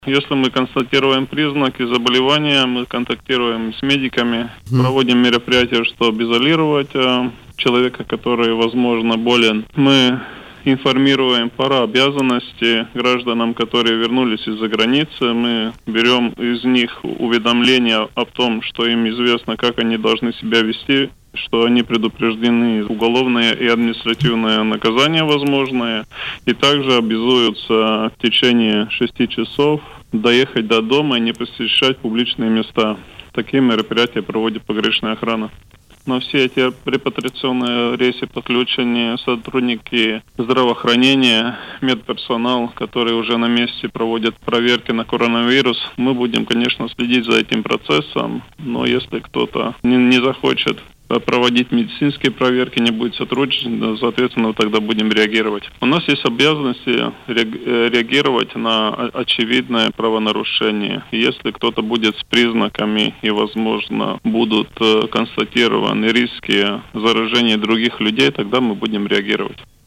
Об этом в интервью радио Baltkom рассказал начальник Госпогранохраны, генерал Гунтис Пуятс.